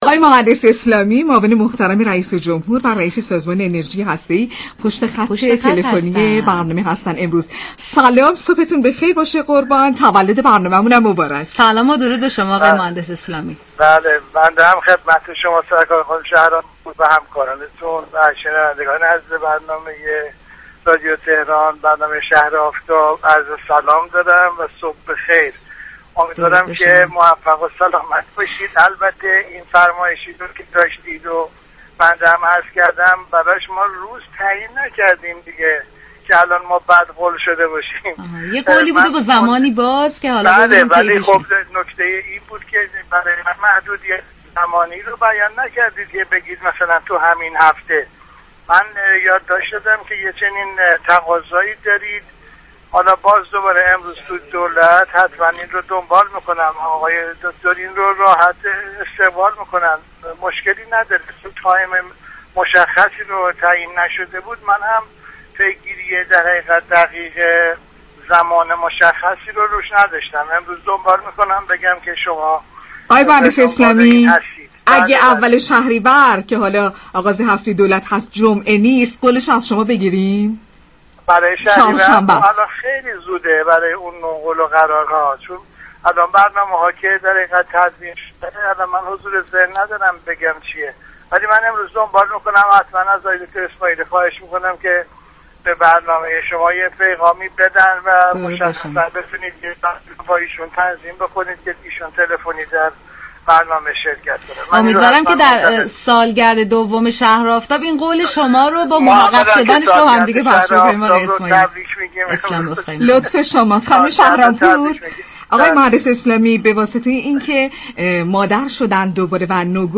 به گزارش پایگاه اطلاع رسانی رادیو تهران، محمد اسلامی معاون رئیس‌جمهور و رئیس سازمان انرژی اتمی در گفت و گو با «شهر آفتاب» اظهار داشت: صنعت هسته‌ای در تمامی امور زندگی مردم اثرگذار است؛ تولید برق به كمك فناوری هسته‌ای یكی از دستاوردهای مهم انرژی اتمی در دنیا و ایران است.